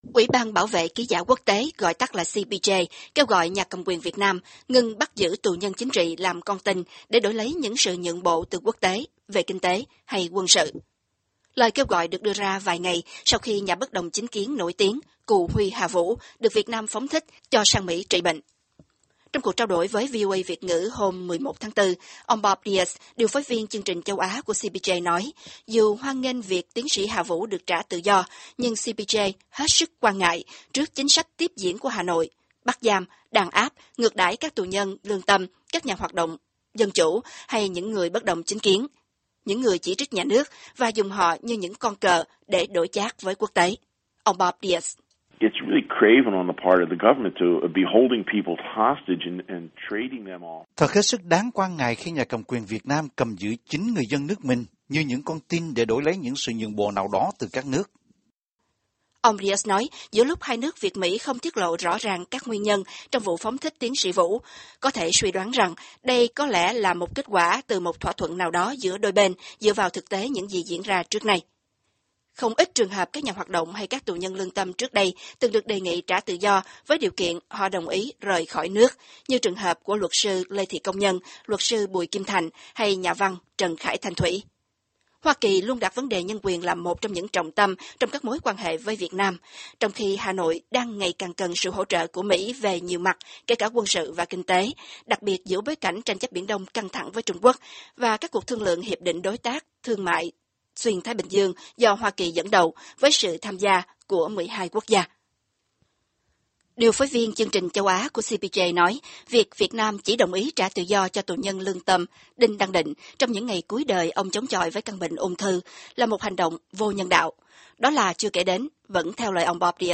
Trong cuộc trao đổi với VOA Việt ngữ hôm 11/4